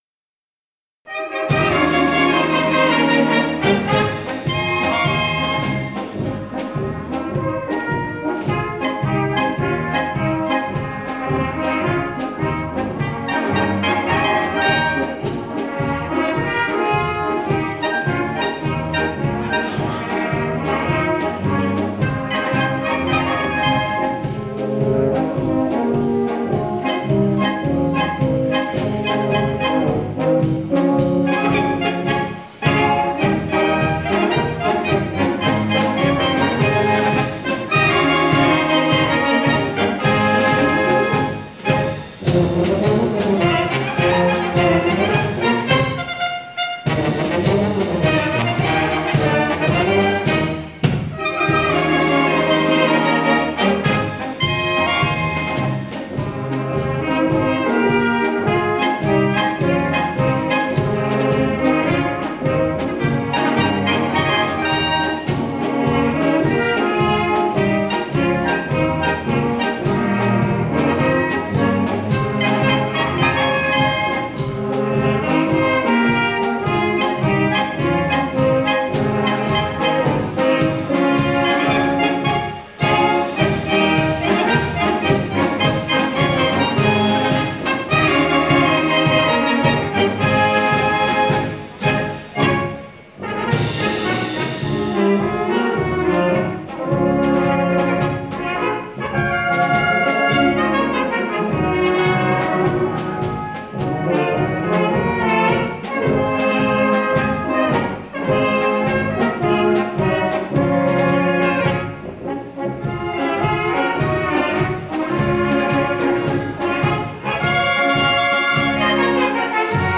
Polka mit Schmiss. gutklingend und nicht all zu schwer.